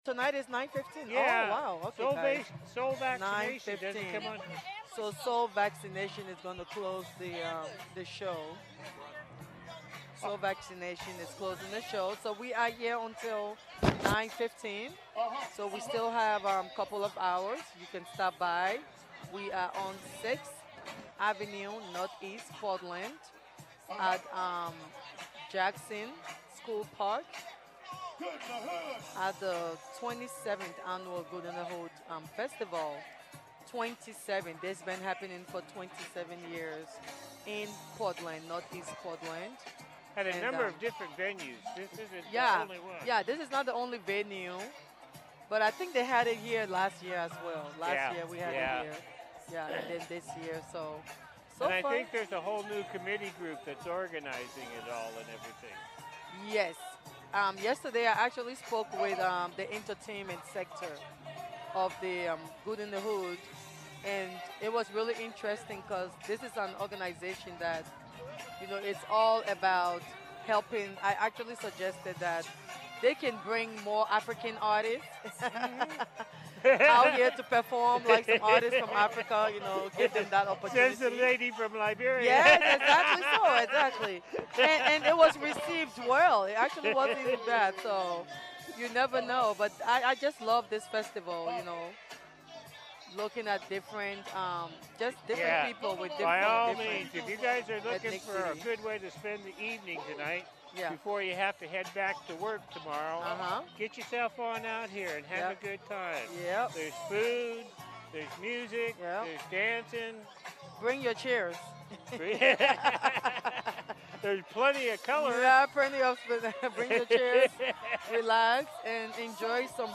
KBOO will be broadcasting LIVE from Good in the Hood from 12 pm to 8 pm.